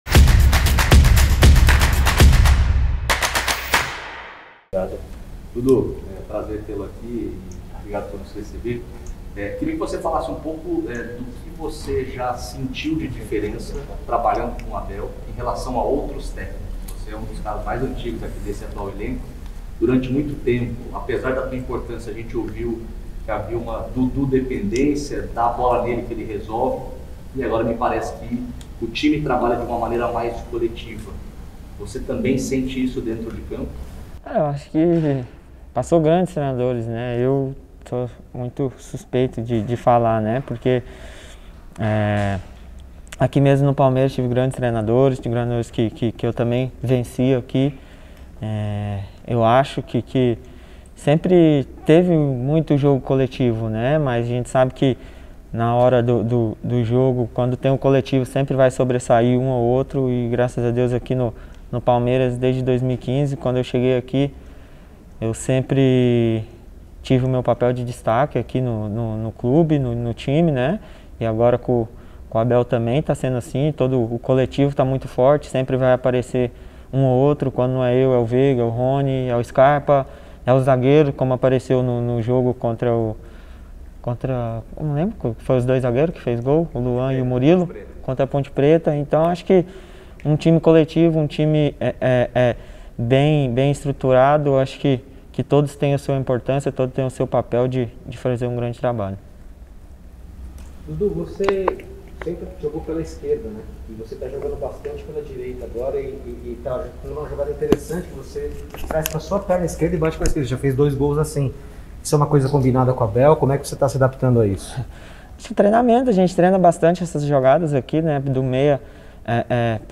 COLETIVA-_-DUDU-_-ABU-DHABI.mp3